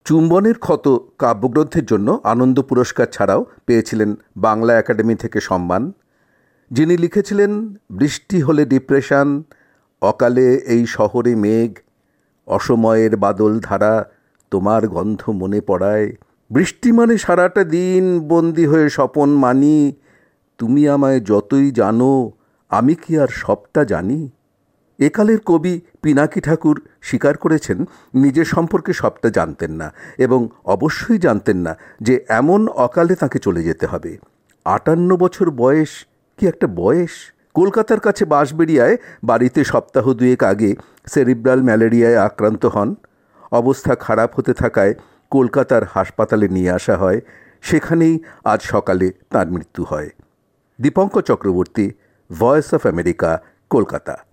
কলকাতা থেকে
রিপোর্ট